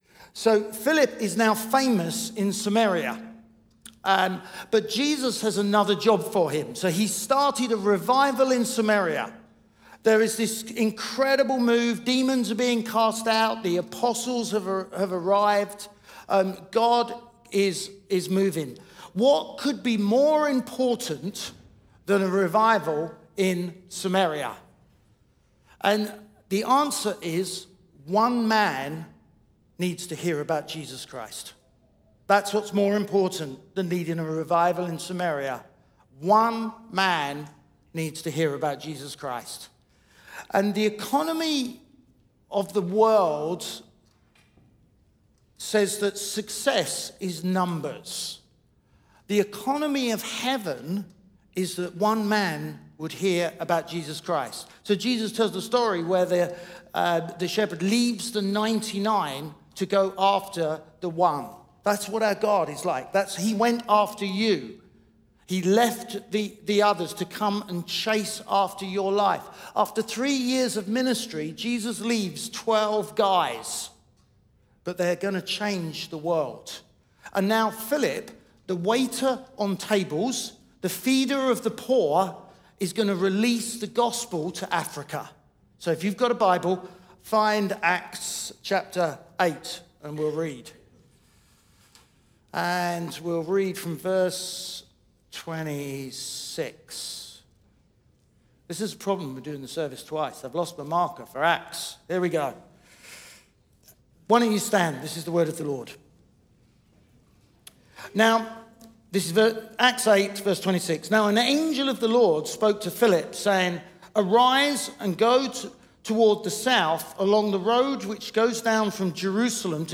Chroma Church Live Stream
Chroma Church - Sunday Sermon Philip - Learn To Preach Christ!